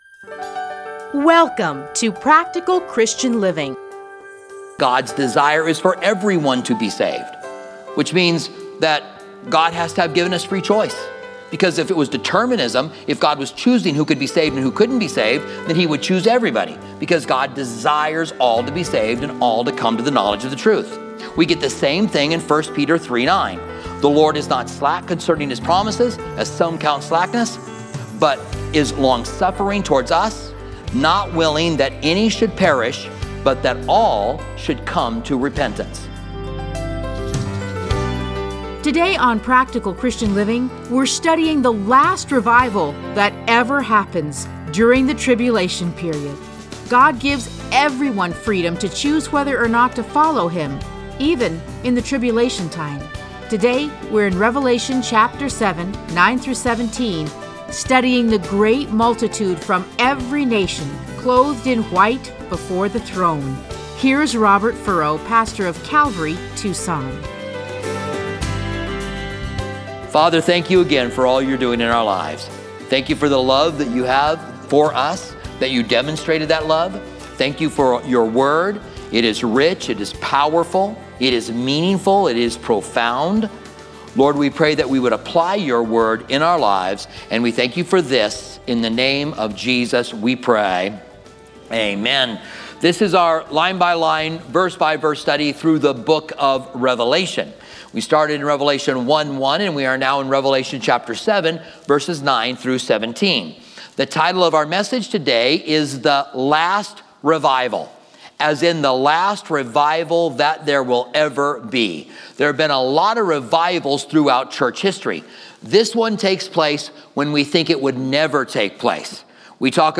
Listen to a teaching from Revelation 7:9-17.